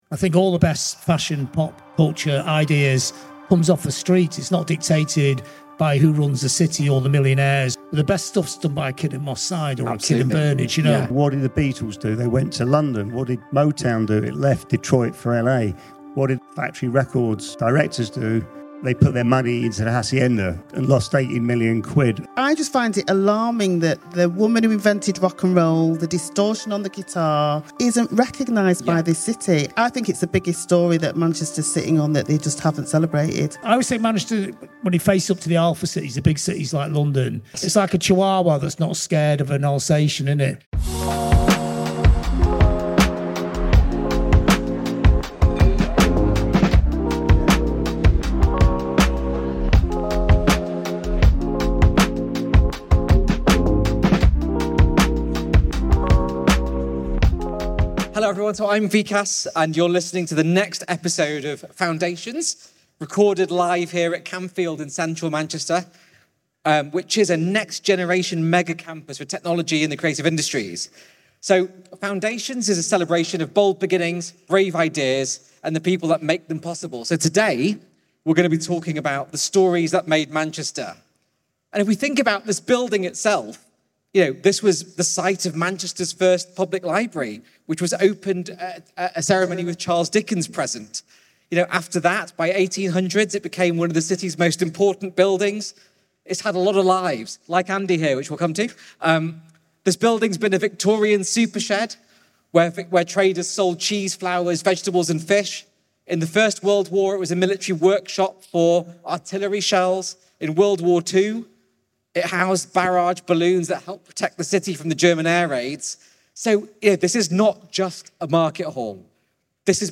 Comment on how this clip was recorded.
Recorded at Campfield - a next generation campus for technology and the creative industries in the heart of Manchester. Each episode dives into the stories behind groundbreaking projects and the minds that drive them.